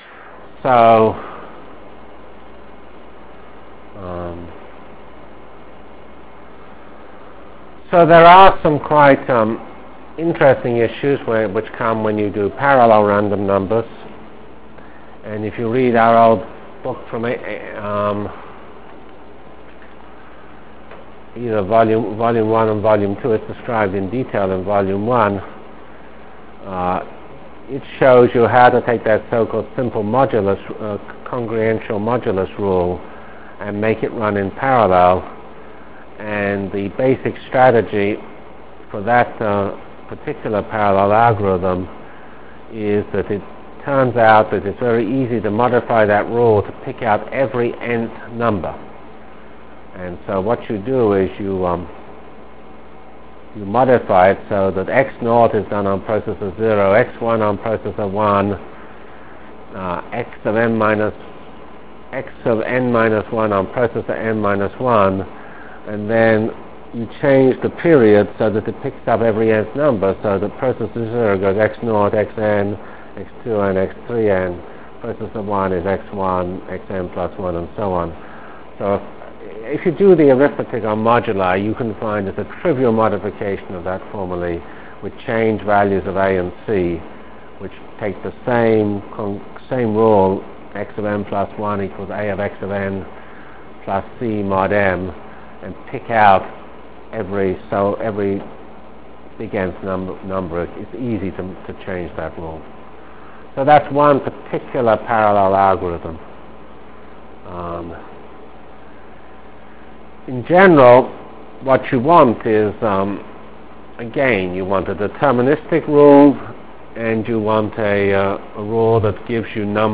Delivered Lectures of CPS615 Basic Simulation Track for Computational Science -- 22 October 96.